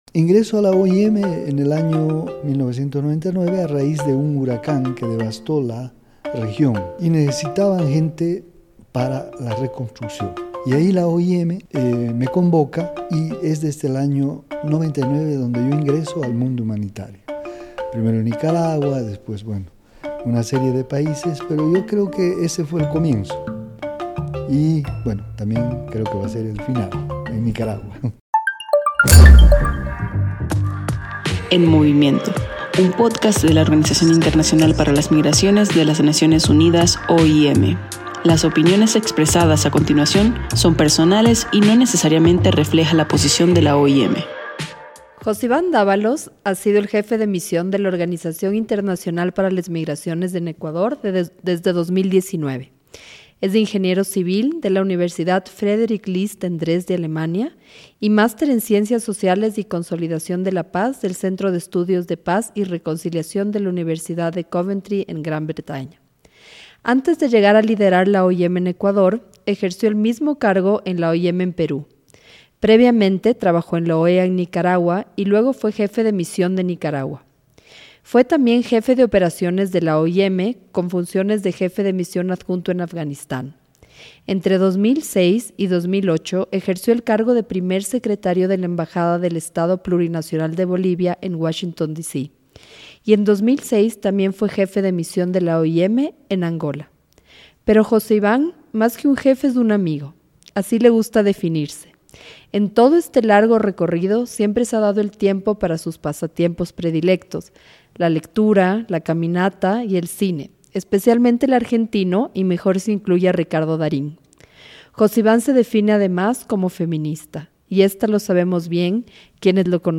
“En Movimiento” es una serie de pódcast de la Organización Internacional para las Migraciones (OIM) que combina la participación de personas migrantes y de especialistas en el tema migratorio para sensibilizar sobre este fenómeno en Centroamérica, Norteamérica y el Caribe.